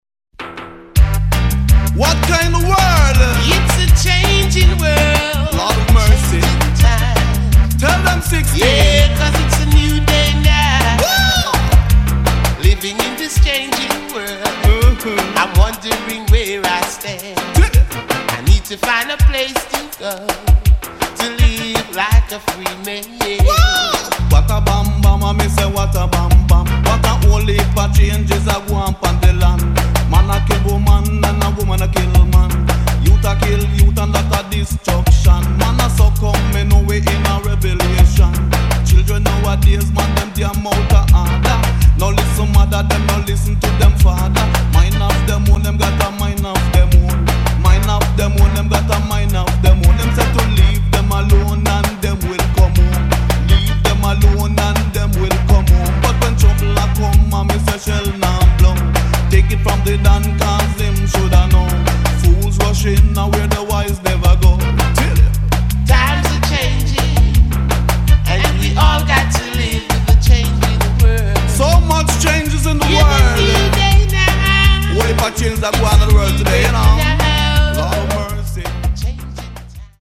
[ REGGAE | DUB ]